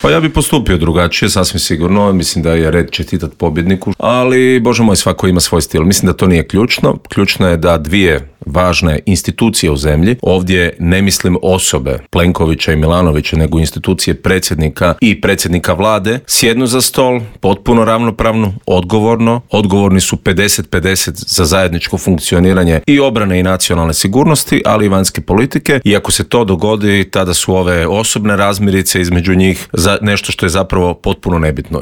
U studiju Media servisa ugostili smo nezavisnog kandidata za gradonačelnika Zagreba Davora Bernardića kojeg uvjerljivi trijumf Milanovića nije iznenadila jer su trendovi bili neupitni: